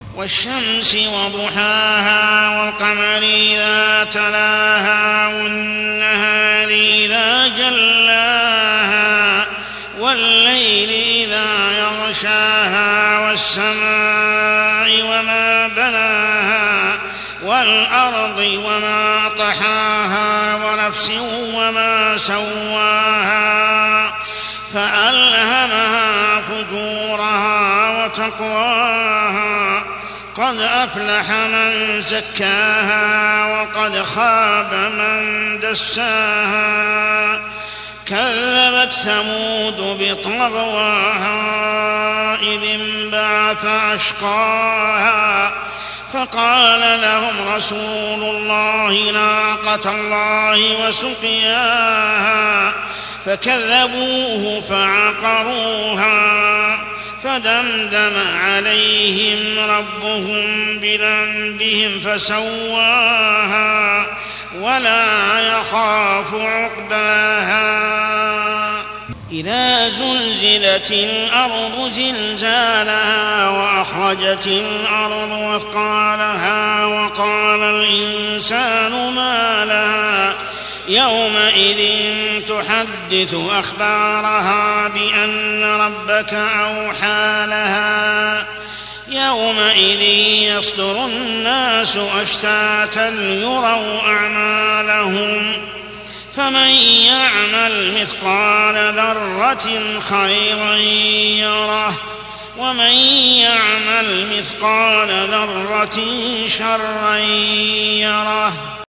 عشائيات شهر رمضان 1424هـ سورتي الشمس و الزلزلة كاملة | Isha prayer Surah Ash-Shams and Az-Zalzalah > 1424 🕋 > الفروض - تلاوات الحرمين